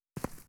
footstep_earth_right.wav